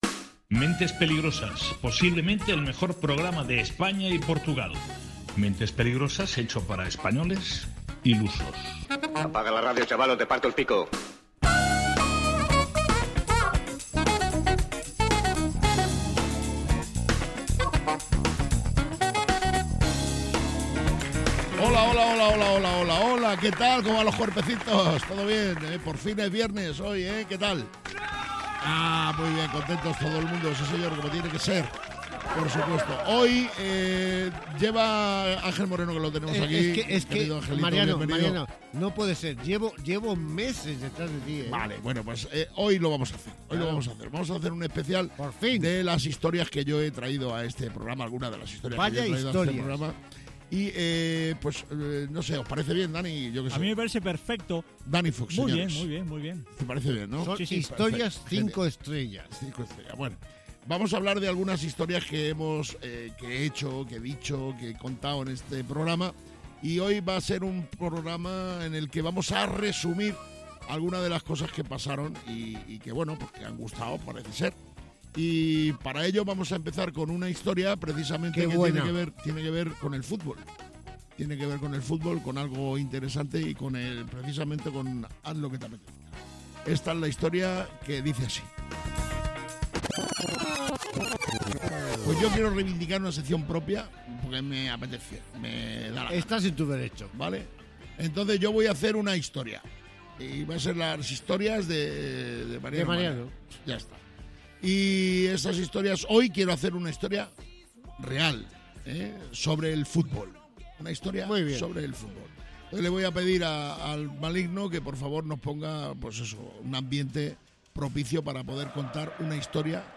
Mentes Peligrosas de Mariano Mariano es un programa de radio en el que cada día se presenta una nueva aventura o no, depende siempre del estado anímico de los participantes en el mismo.